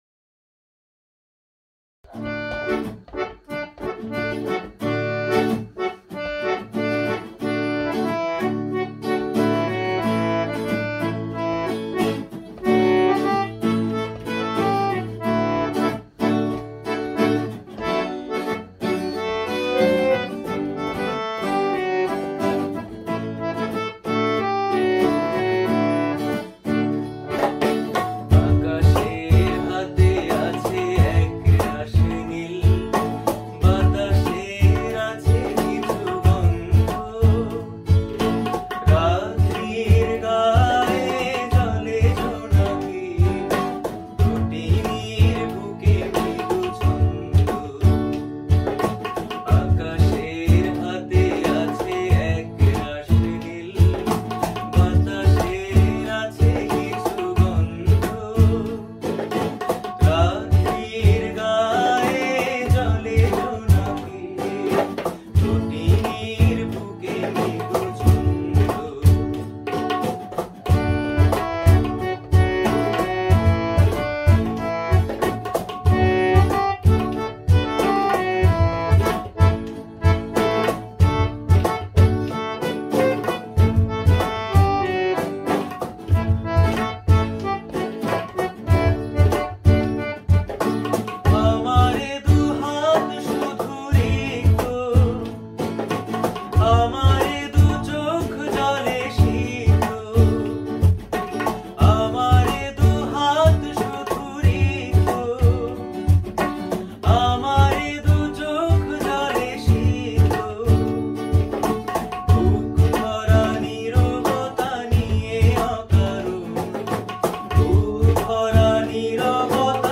Bengali Traditional Folk Song